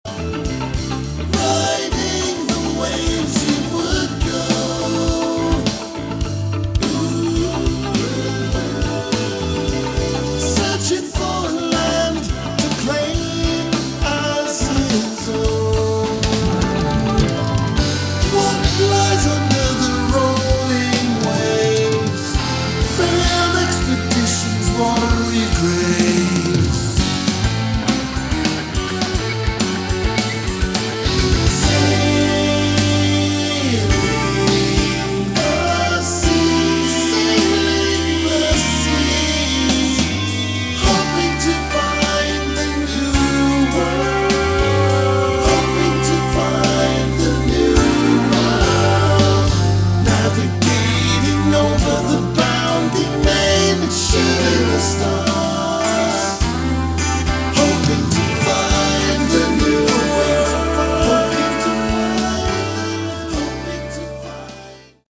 lead and backing vocals, guitars, drums